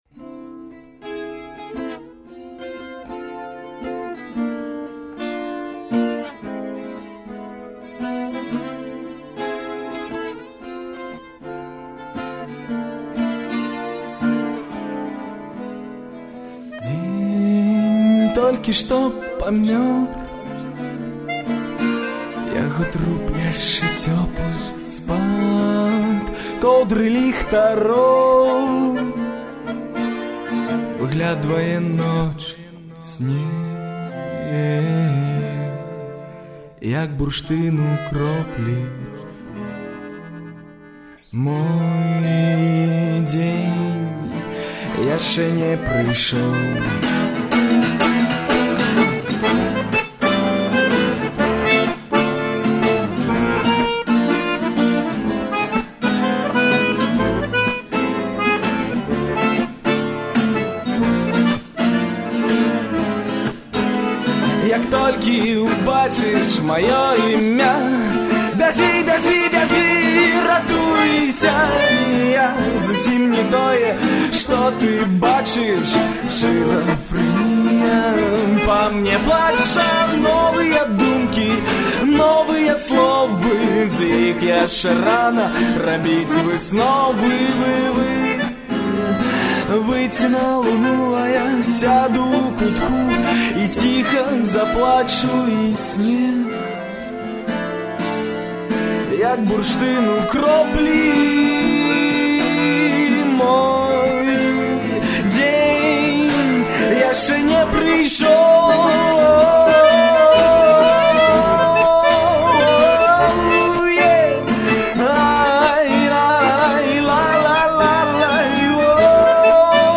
архіўны запіс